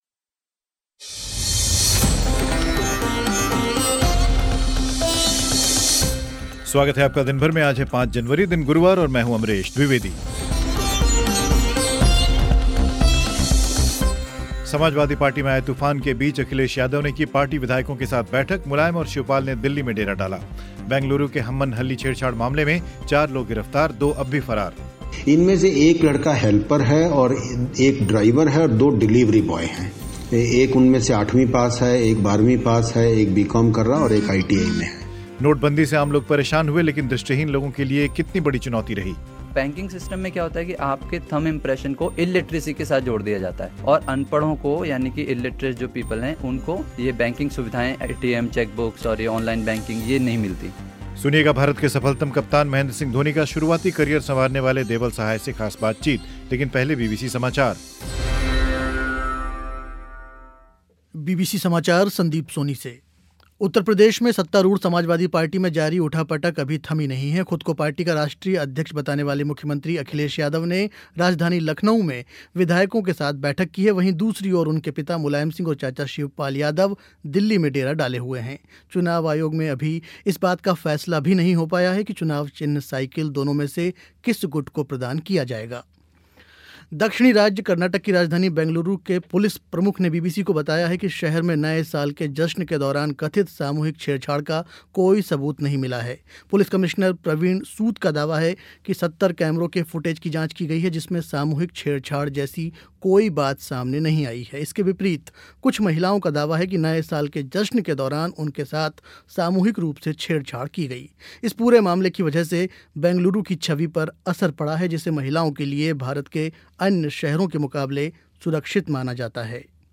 खास बातचीत